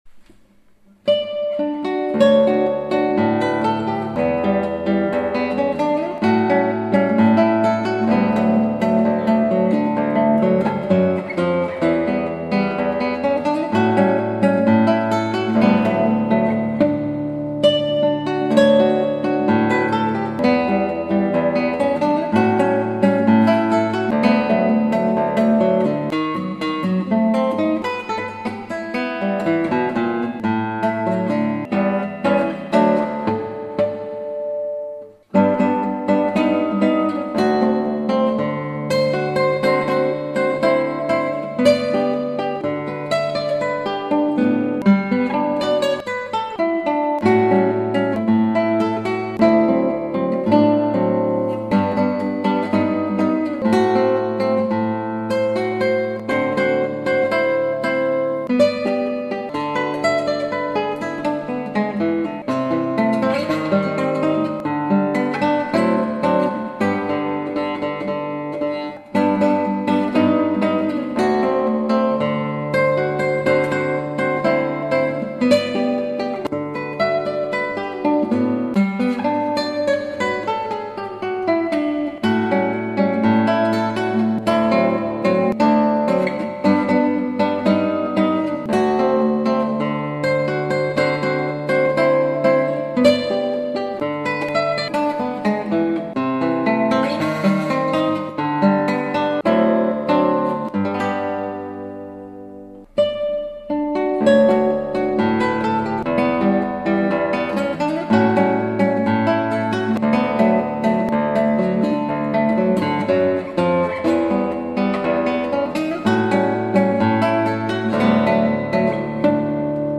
ショーロ、鐘の響き